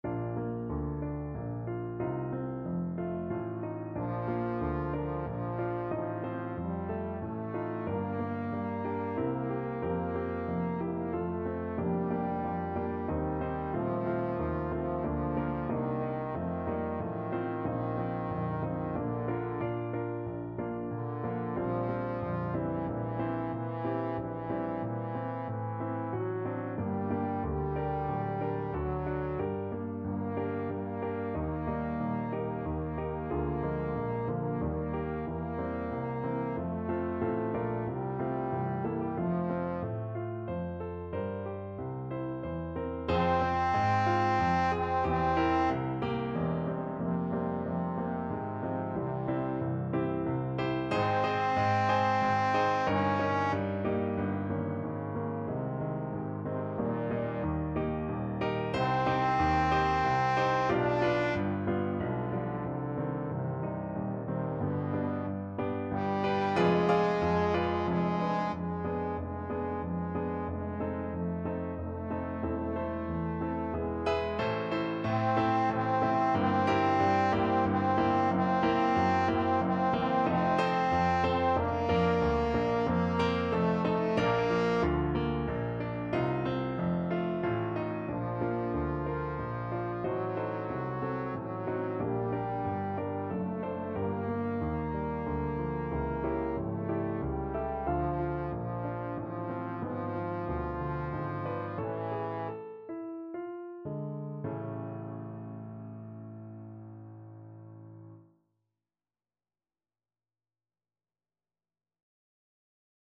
Hostias Trombone version
Trombone
Bb major (Sounding Pitch) (View more Bb major Music for Trombone )
3/4 (View more 3/4 Music)
~ = 92 Larghetto
Classical (View more Classical Trombone Music)
mozart_requiem_hostias_TBNE.mp3